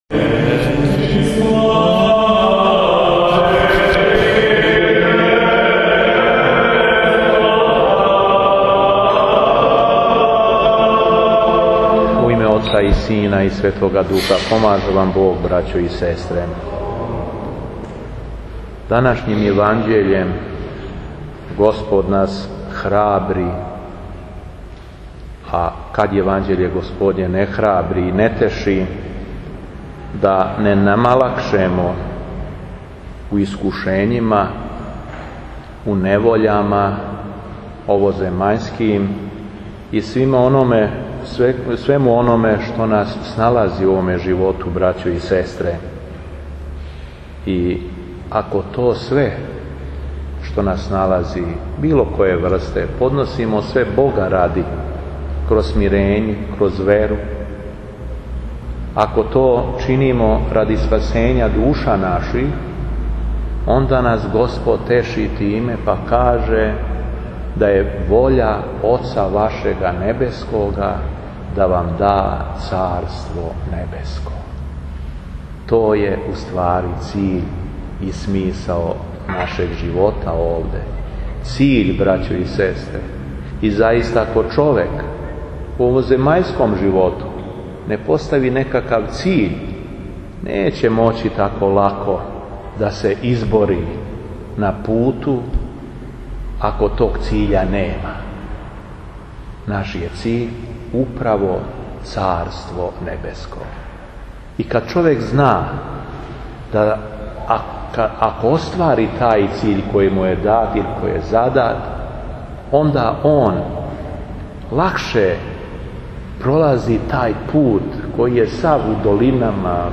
ЛИТУРГИЈА У ХРАМУ СВЕТОГ САВЕ НА АЕРОДРОМУ - Епархија Шумадијска
Беседа епископа шумадијског Г. Јована